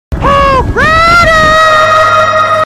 Hog Rider Sound Effect - Botón de Efecto Sonoro